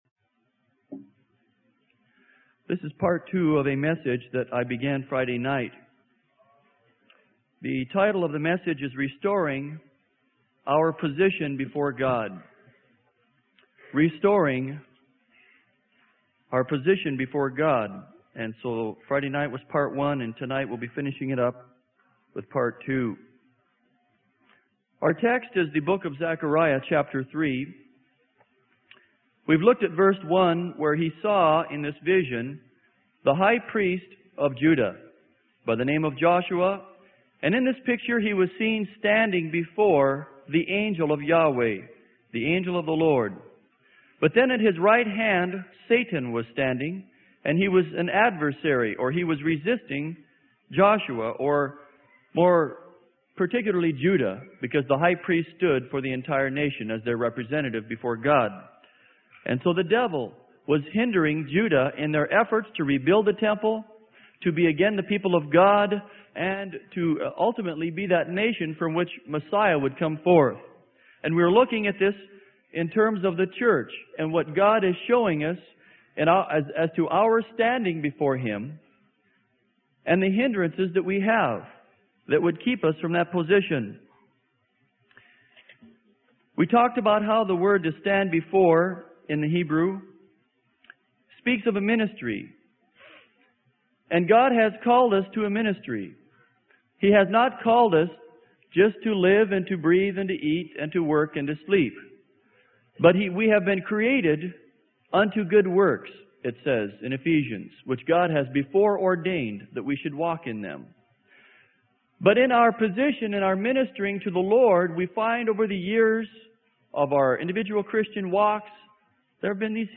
Sermon: Restoring Our Position Before God - Part 2 - Freely Given Online Library